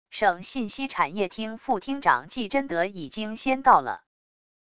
省信息产业厅副厅长纪真德已经先到了wav音频生成系统WAV Audio Player